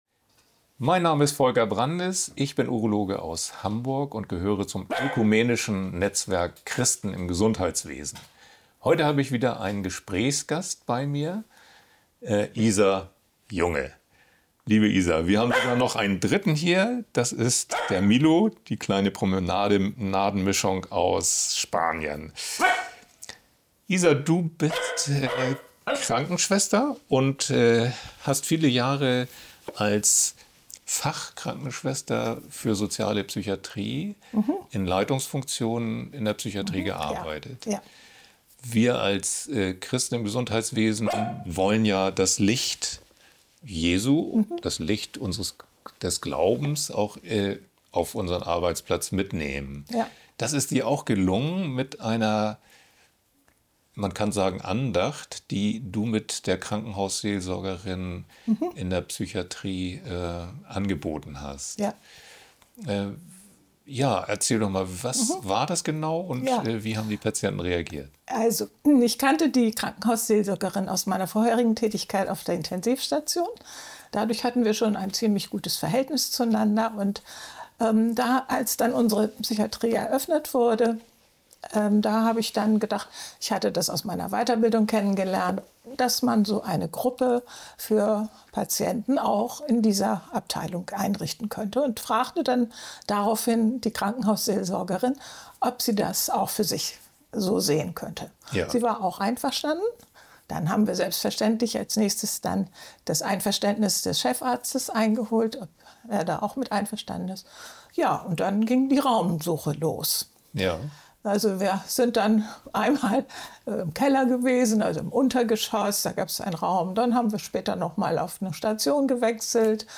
Interviewpartner